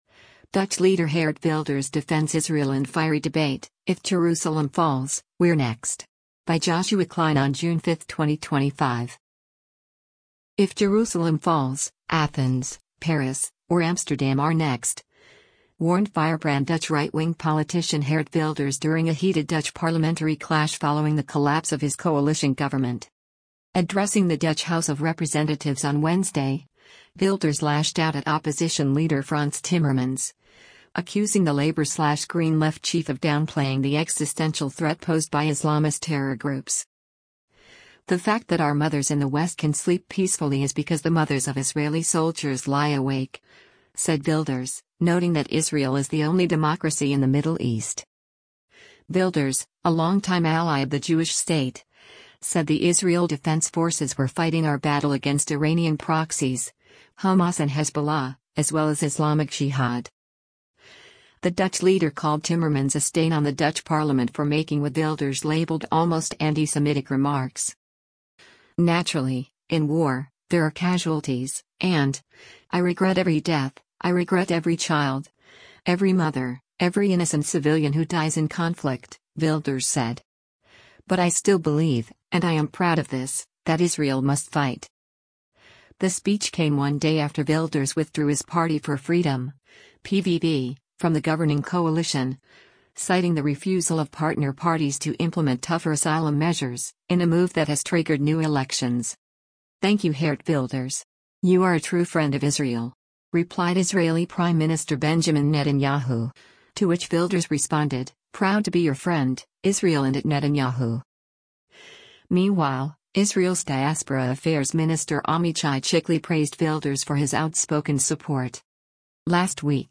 “If Jerusalem falls, Athens, Paris, or Amsterdam are next,” warned firebrand Dutch right-wing politician Geert Wilders during a heated Dutch parliamentary clash following the collapse of his coalition government.
Addressing the Dutch House of Representatives on Wednesday, Wilders lashed out at opposition leader Frans Timmermans, accusing the Labor/GreenLeft chief of downplaying the existential threat posed by Islamist terror groups.